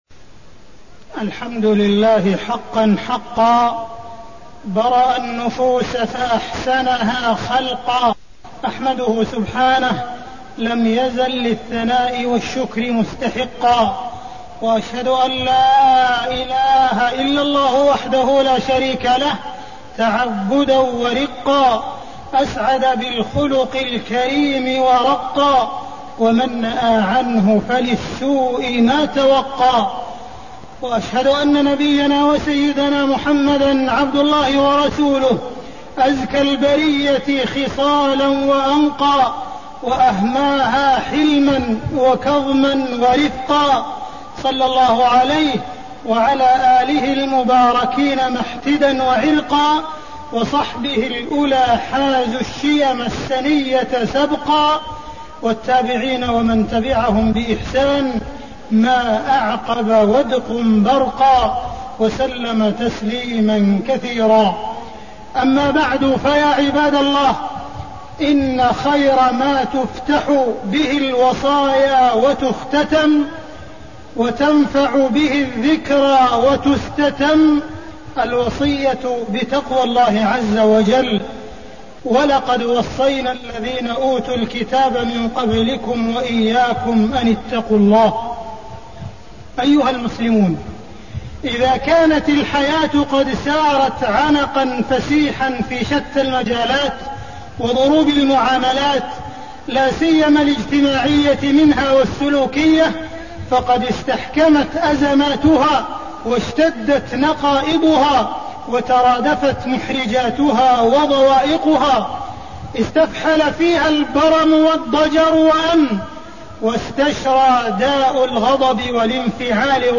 تاريخ النشر ٩ جمادى الأولى ١٤٣١ هـ المكان: المسجد الحرام الشيخ: معالي الشيخ أ.د. عبدالرحمن بن عبدالعزيز السديس معالي الشيخ أ.د. عبدالرحمن بن عبدالعزيز السديس خطورة الغضب